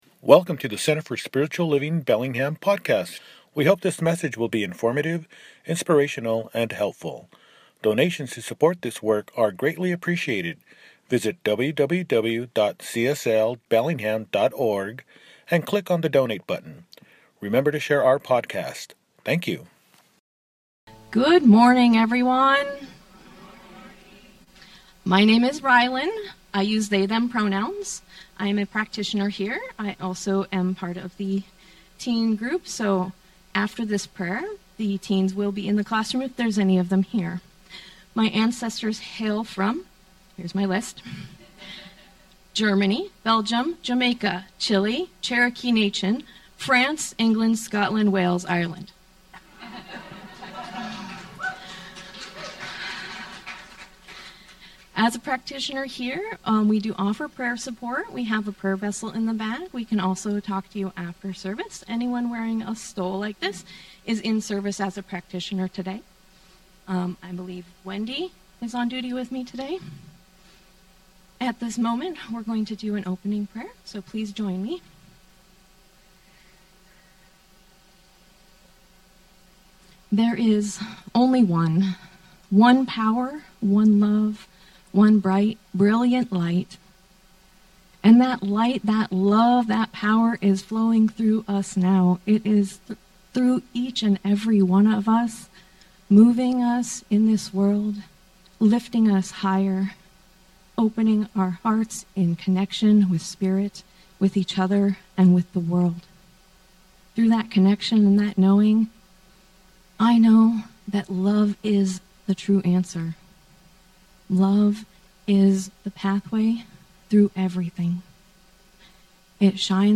Want To Change the World_ Do It! – Second Service
Apr 19, 2026 | Podcasts, Services